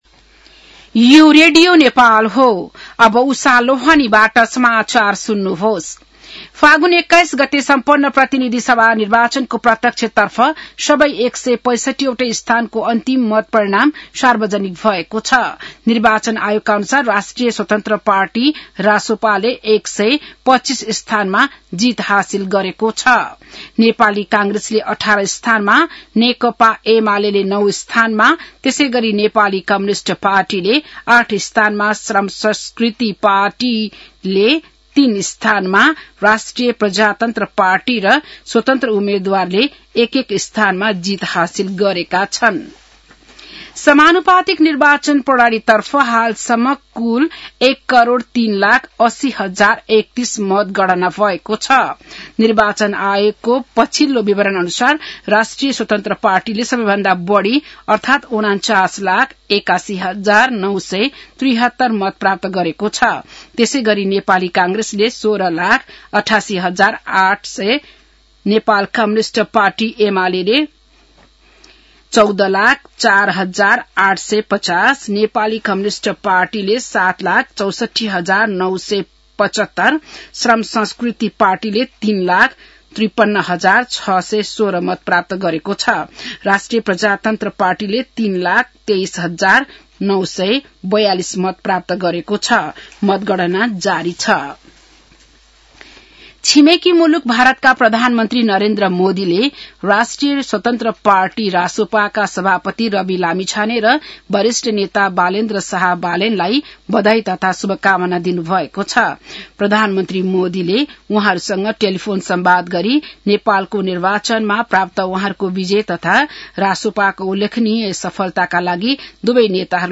बिहान १० बजेको नेपाली समाचार : २६ फागुन , २०८२